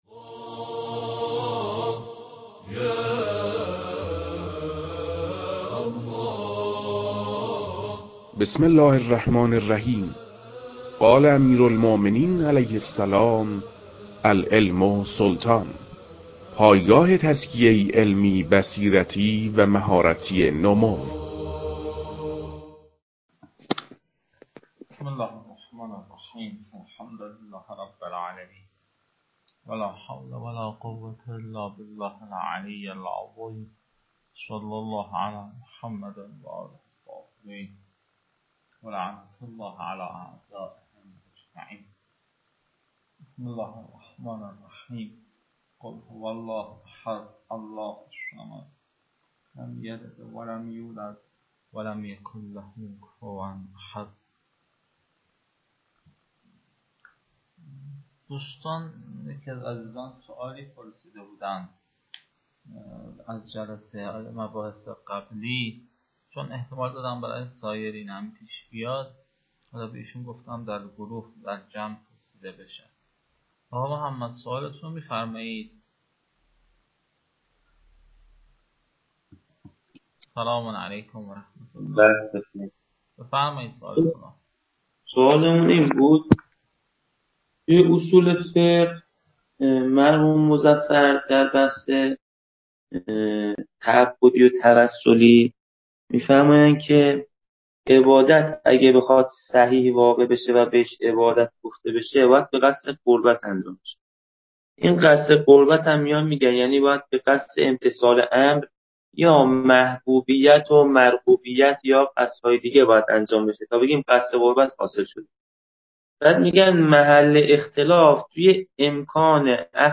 در این بخش، فایل های مربوط به تدریس مبحث رسالة في القطع از كتاب فرائد الاصول متعلق به شیخ اعظم انصاری رحمه الله